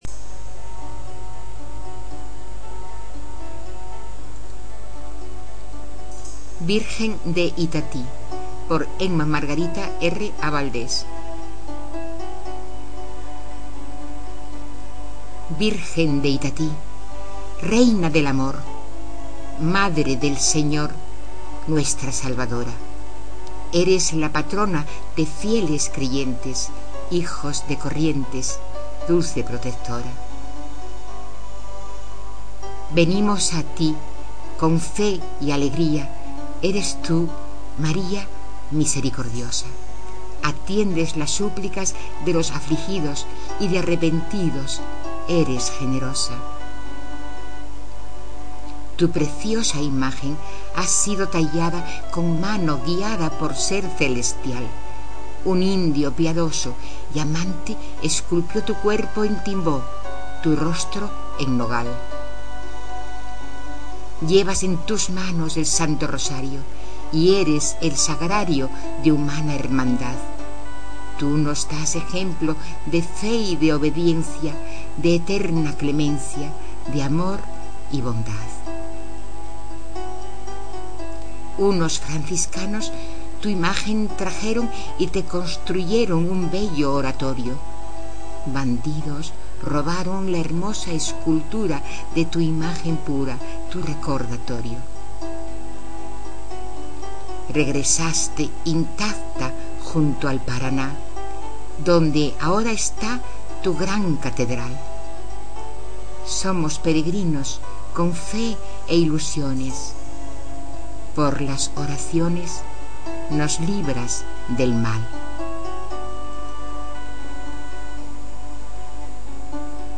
recitadas por la autora.